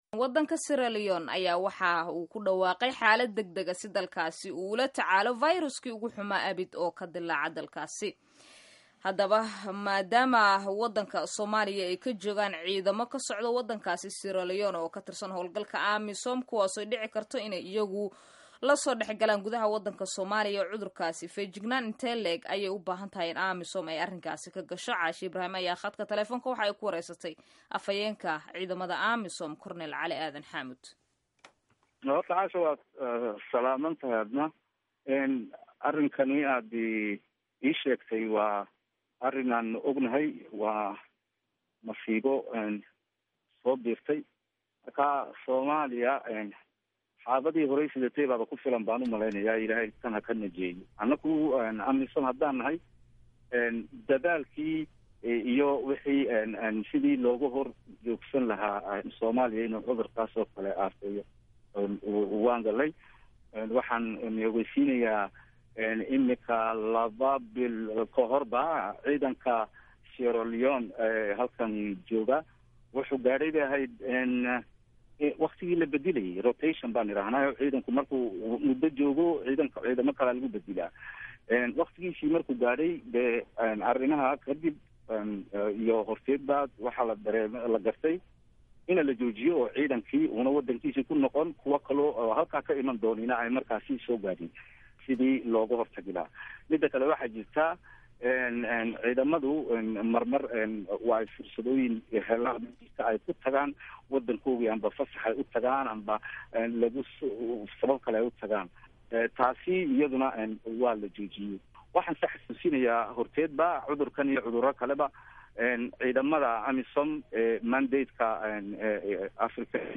WAREYSIYO EBOLA